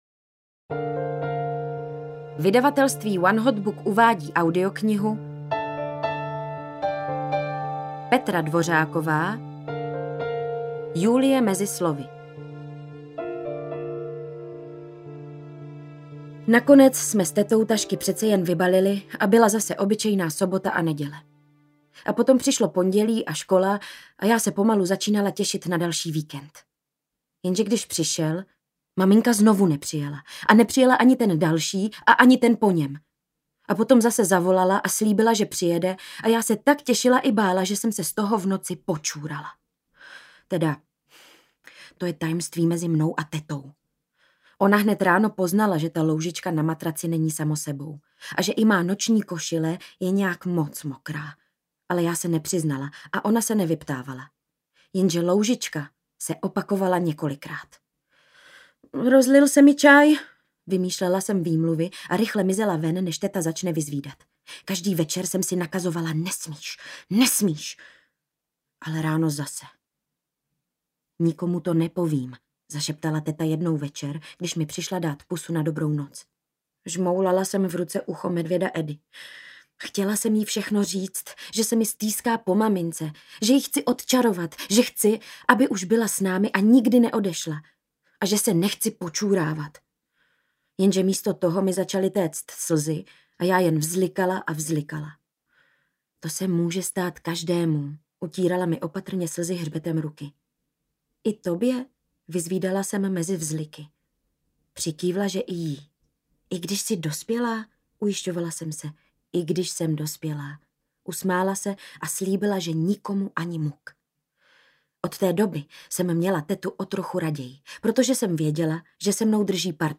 Julie mezi slovy audiokniha
Ukázka z knihy